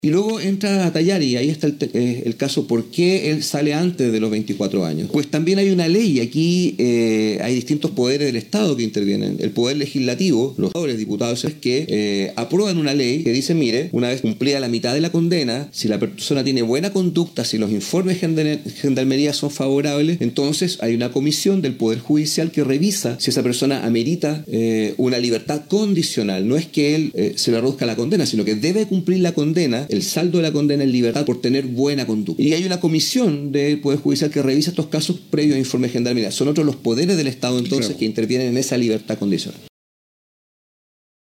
La mañana de este lunes, el fiscal Christian González Carriel, sostuvó una entrevista exclusiva con Nostálgica donde explicó cómo se está desarrollando la investigación de los casos de las mujeres desaparecidas que han conmocionado a la comunidad del norte del país, la cual muchas veces han generado inquietudes o cierta desinformación en relación  a los hechos.